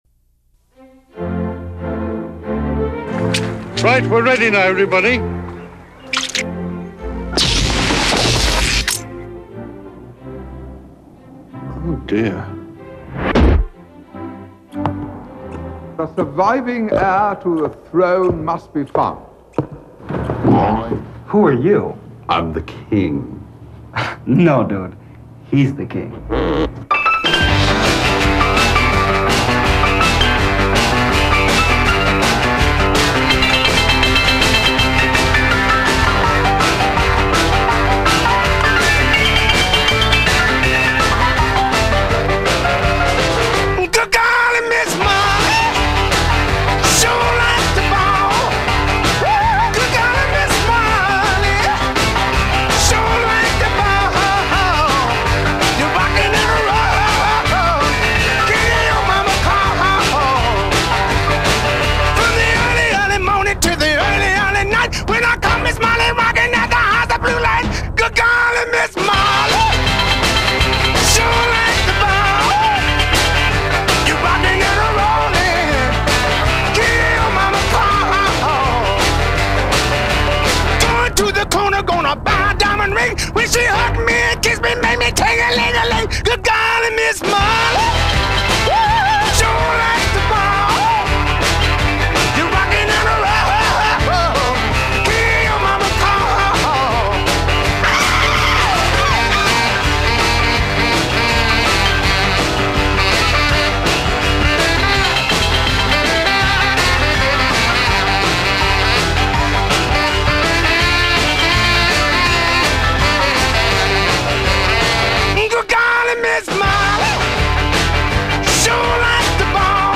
но звук желает лучшего.